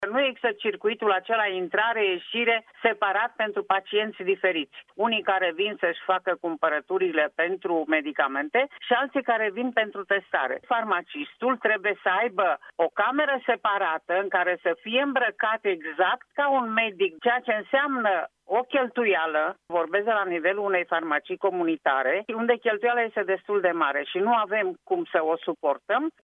30mar-13-farmacista-nu-exista-circuite-si-nu-avem-bani-de-costume.mp3